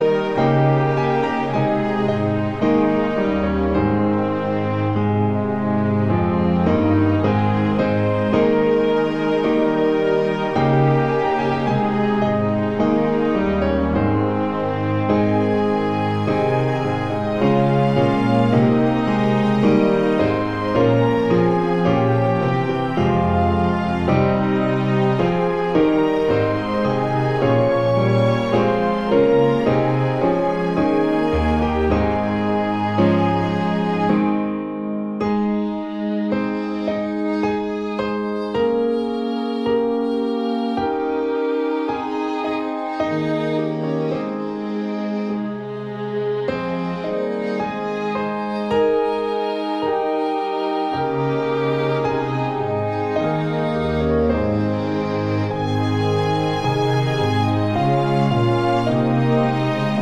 Chormusik/Evangeliumslieder